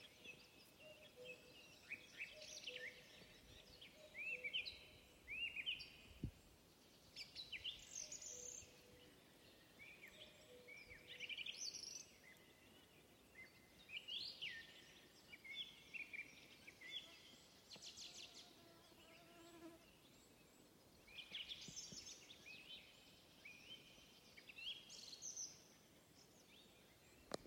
Birds -> Thrushes ->
Song Trush, Turdus philomelos
StatusSinging male in breeding season